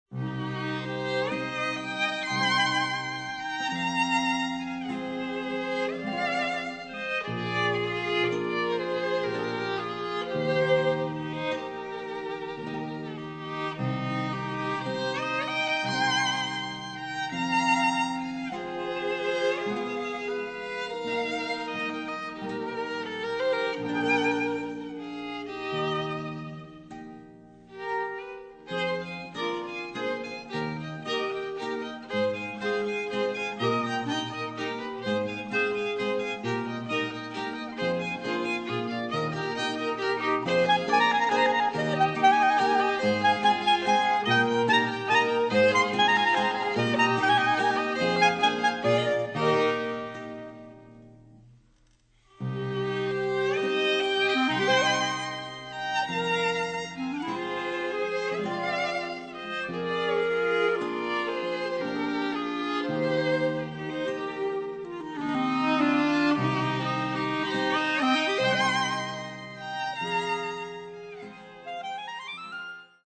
Die Schrammelgeigen
* Quartett mit Knopfharmonika
Kontragitarre